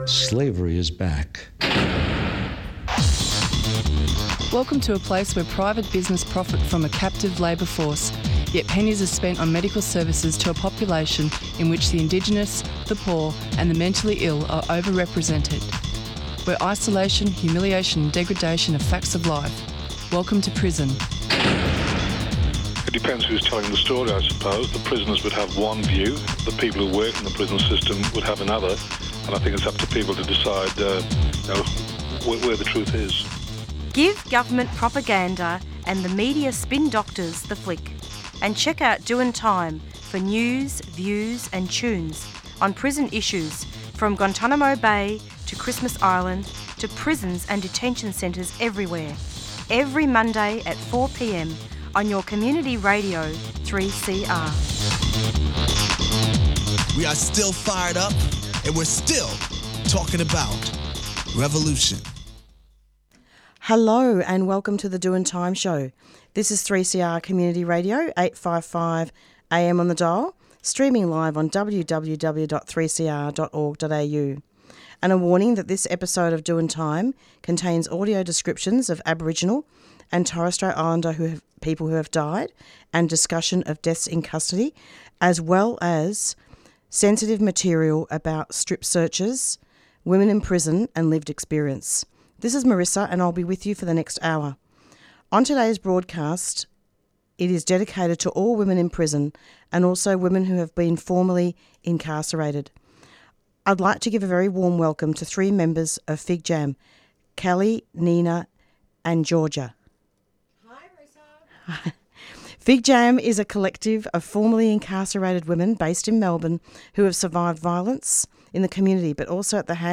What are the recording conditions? Today's broadcast is dedicated to all women in prison, and also women who have been formerly incarcerated.